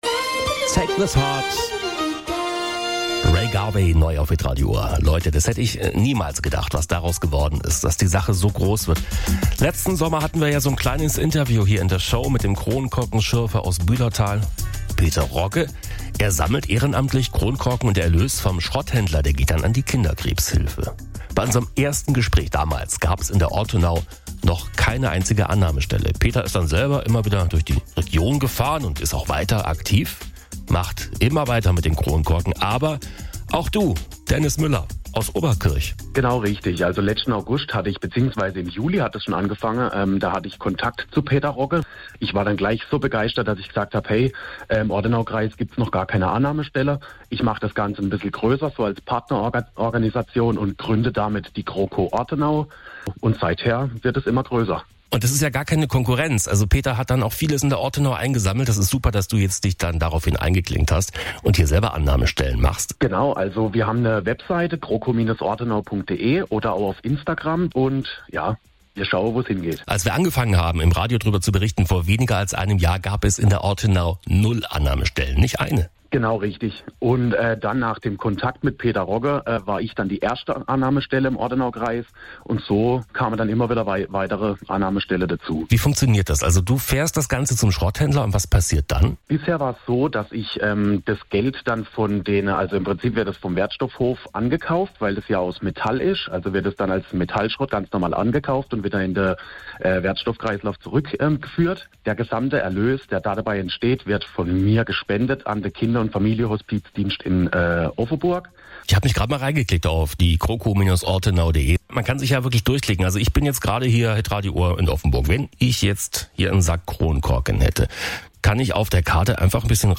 Interview auf HITRADIO OHR:
hitradio-ohr-interview.mp3